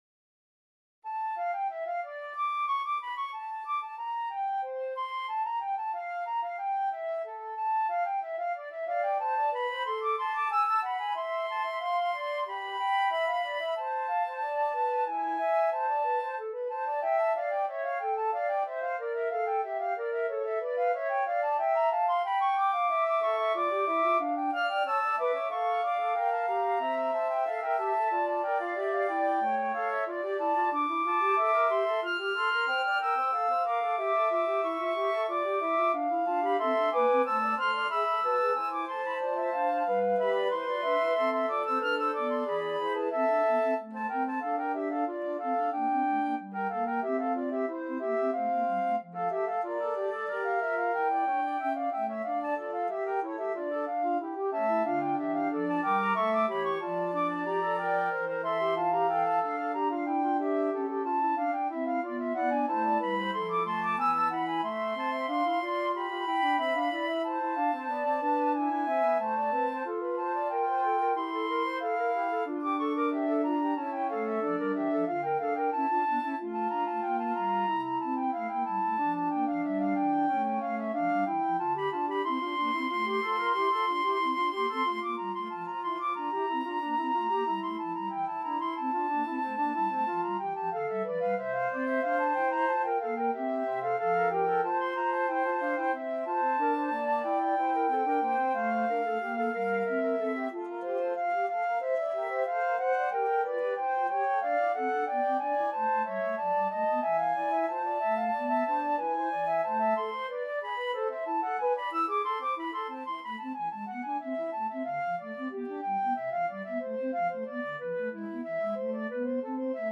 Flute 1Flute 2Alto FluteBass Flute
4/4 (View more 4/4 Music)
Flute Quartet  (View more Advanced Flute Quartet Music)
Classical (View more Classical Flute Quartet Music)